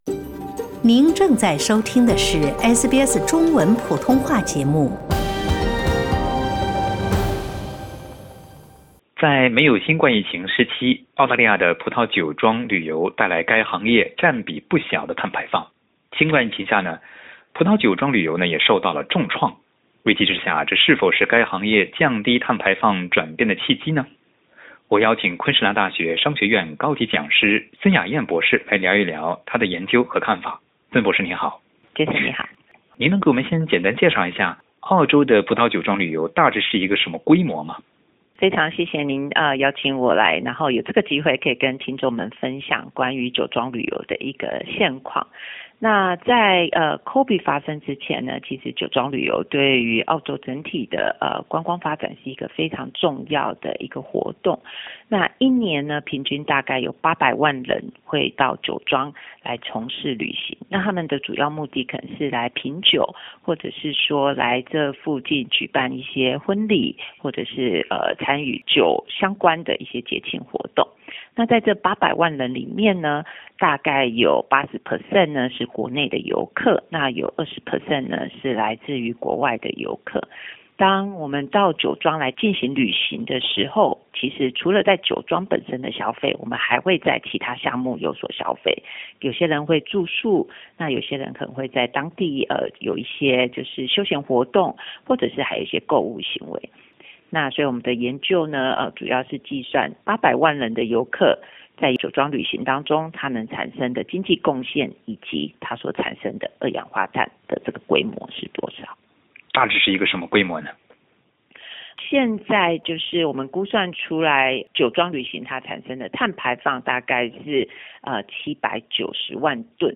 LISTEN TO 研究：重视葡萄酒旅游国内市场有利行业减排和持续发展 SBS Chinese 09:16 cmn 請您點擊收聽完整的採訪報導。